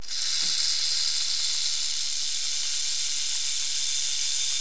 دانلود صدای حیوانات جنگلی 77 از ساعد نیوز با لینک مستقیم و کیفیت بالا
جلوه های صوتی